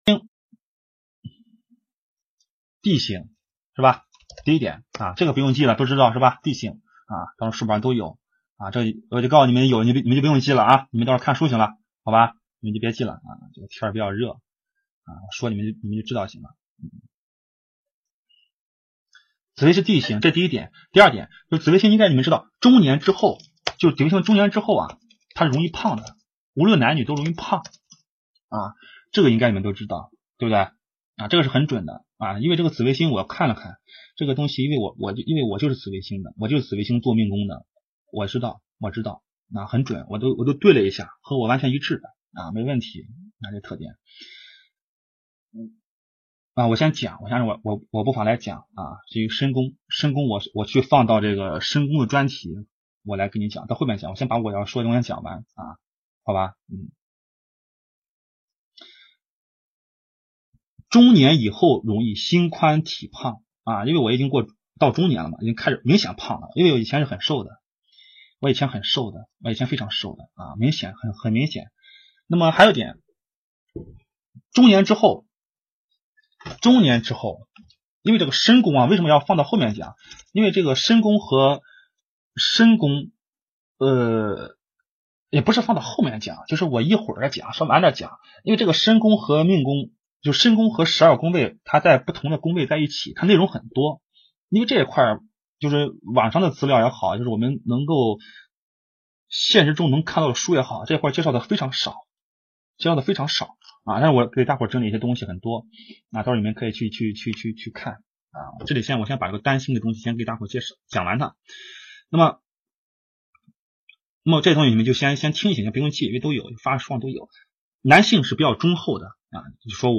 紫藤斋《紫微斗数初级班》录音课程+资料 百度网盘分享